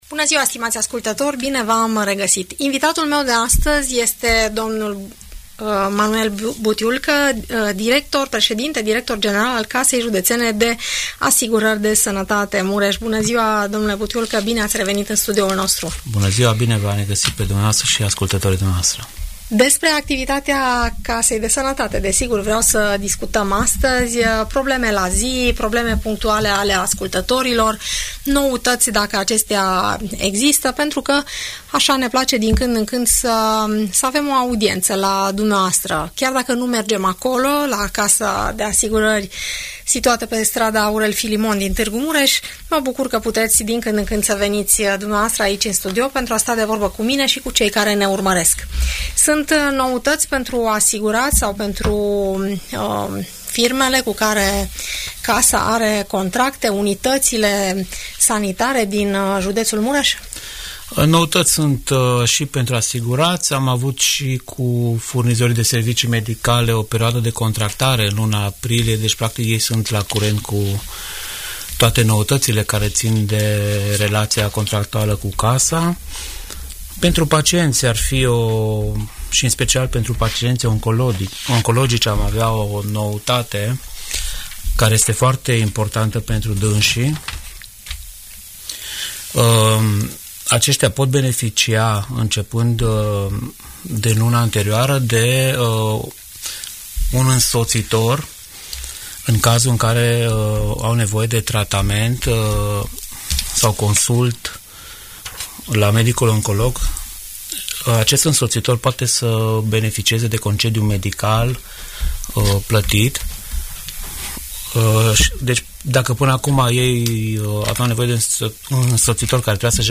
Audiență radio la Casa de Asigurări de Sănătate Mureș